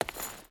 Stone Chain Walk 2.ogg